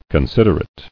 [con·sid·er·ate]